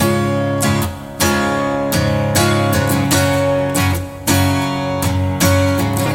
78 Bpm 木吉他
描述：韵律吉他 和弦。C / F
Tag: 78 bpm Acoustic Loops Guitar Acoustic Loops 1.04 MB wav Key : C